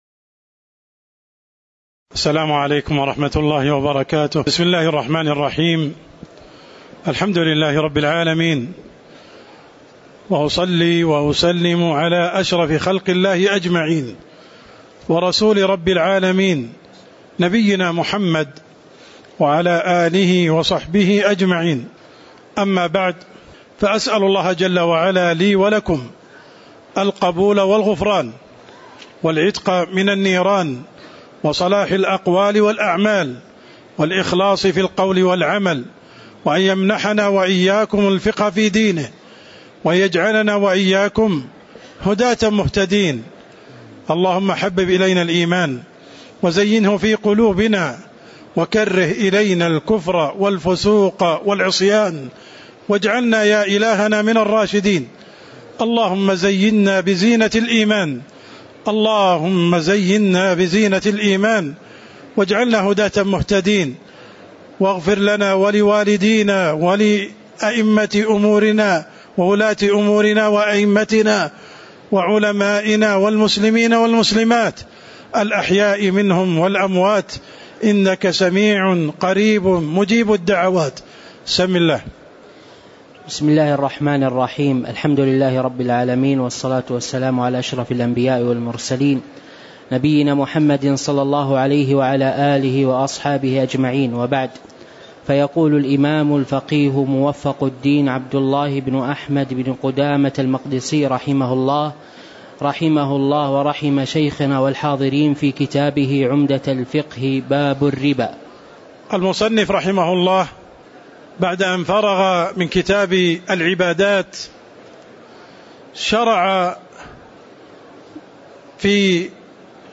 تاريخ النشر ٢٧ ذو القعدة ١٤٤٠ هـ المكان: المسجد النبوي الشيخ